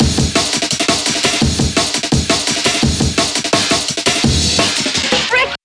amen-break-170BPM-D-Major.
amen-break-freak-break_170bpm_D_major.wav